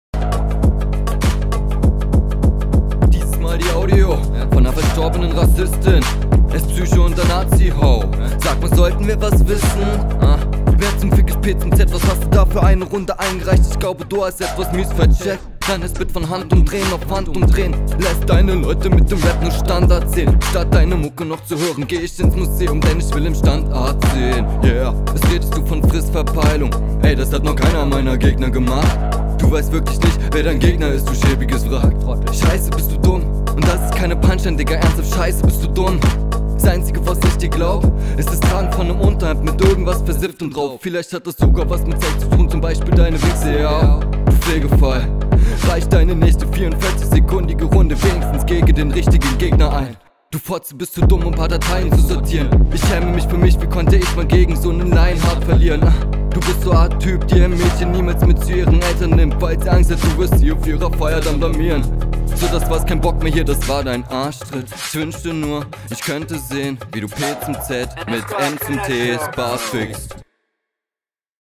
Flow: Am Anfang etwas komisch, später dann besser.